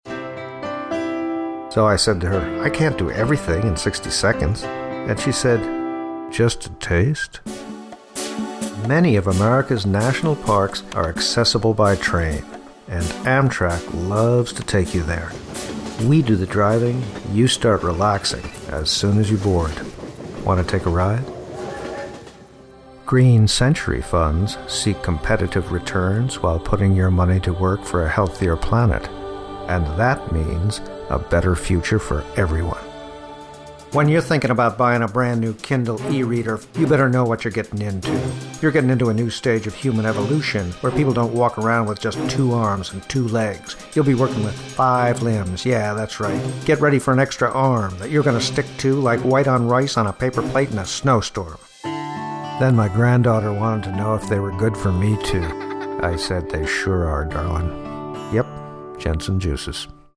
VOICE ACTOR
Commercial demo (1:00)